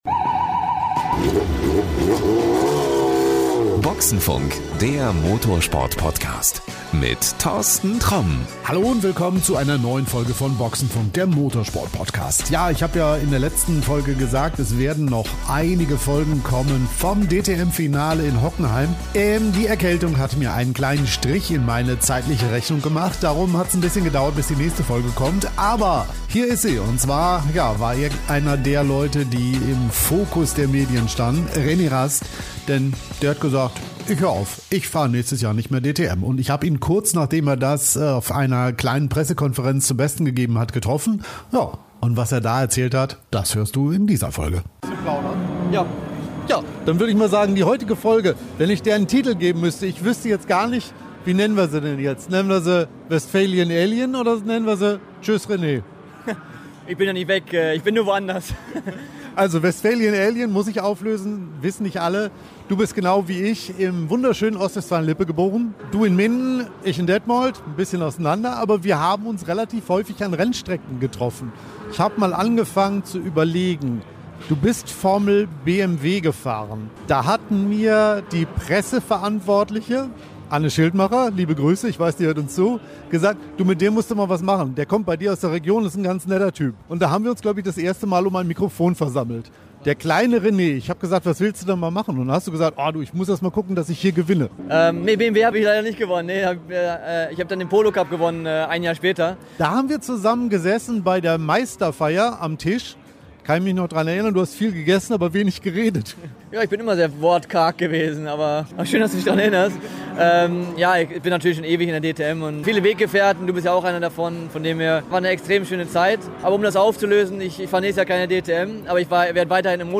Ein tolles Gespräch voller